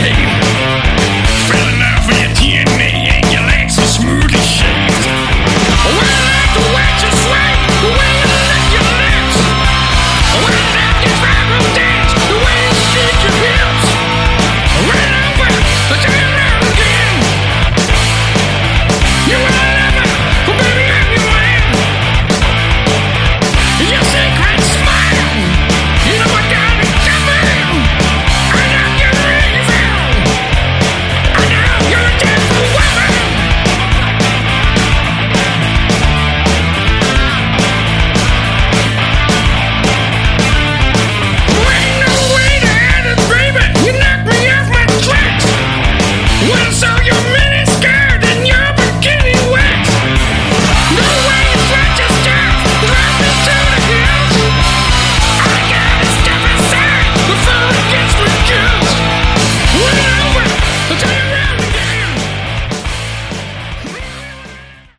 Category: Sleaze Glam